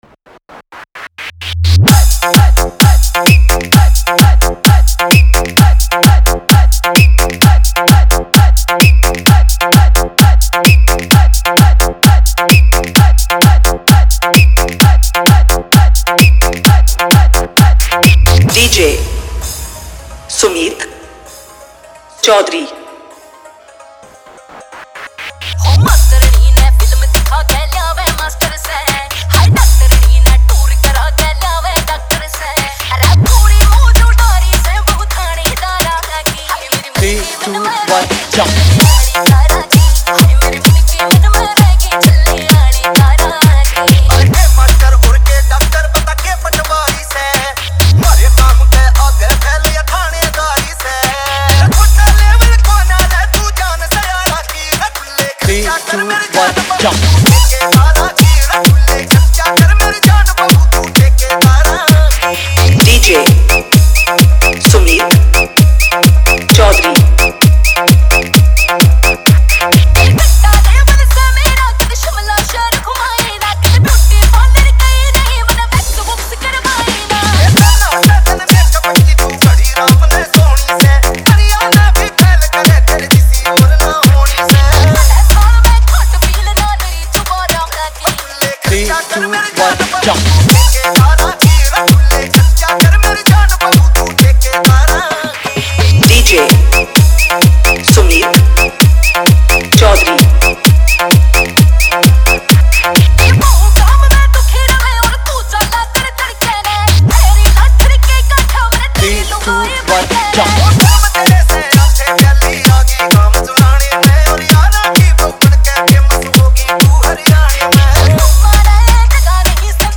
Category : Haryanvi Remix